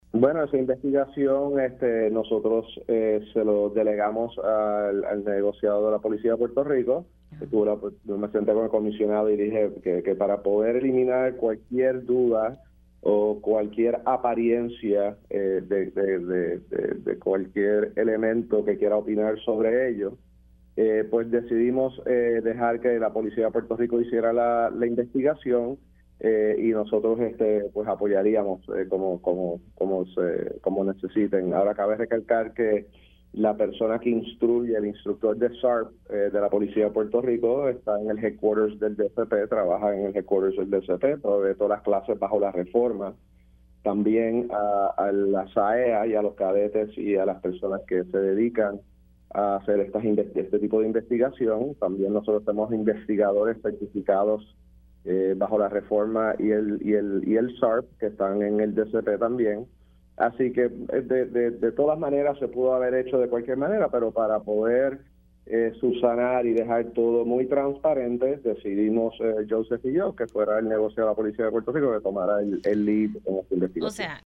El secretario del Departamento de Seguridad Pública (DSP), General Arturo Garffer confirmó en Pega’os en la Mañana que le delegaron la investigación de la escolta de la secretaria de Justicia, Janet Parra al Negociado de la Policía de Puerto Rico (NPPR).